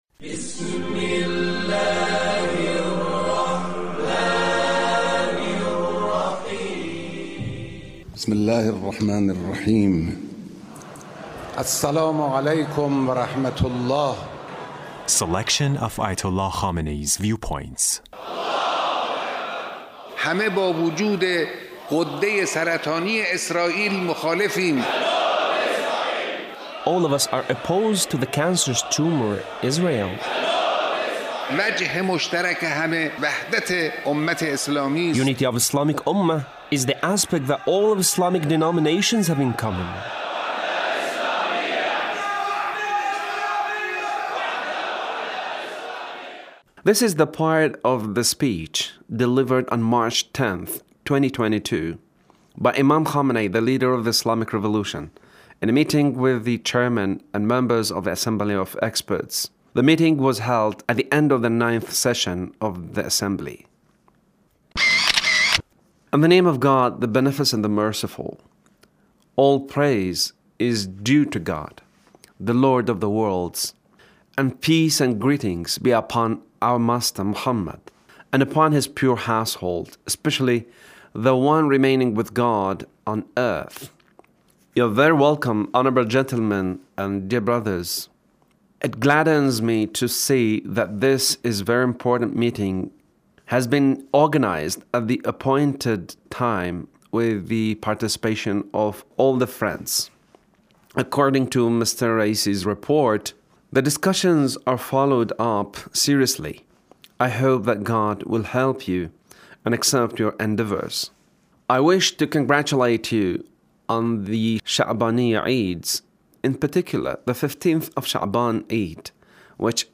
Leader's speech (1351)